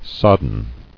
[sod·den]